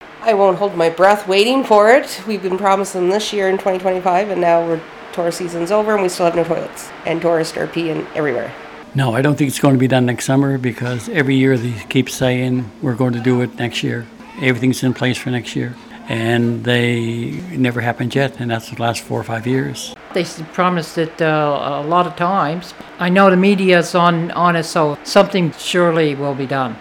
However, some residents interviewed during a vox-pop are skeptical of this timeline, believing that several previous promises regarding this project have not been honored.